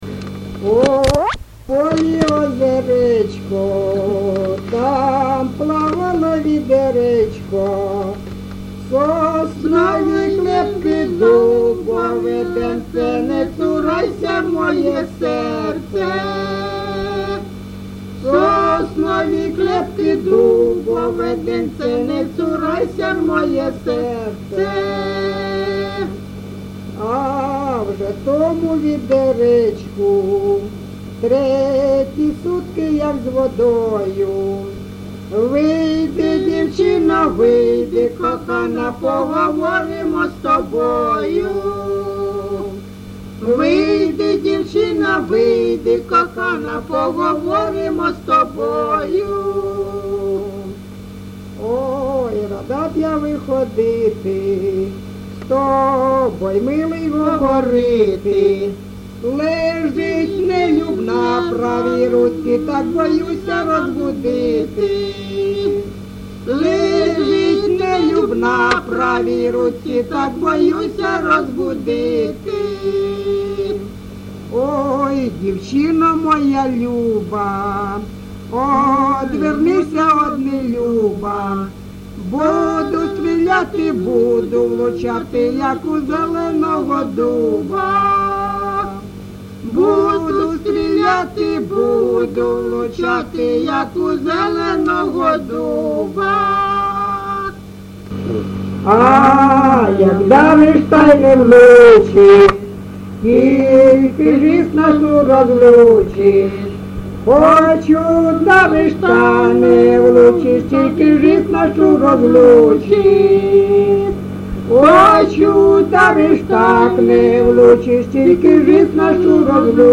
ЖанрПісні з особистого та родинного життя, Козацькі, Балади
Місце записус. Калинове Костянтинівський (Краматорський) район, Донецька обл., Україна, Слобожанщина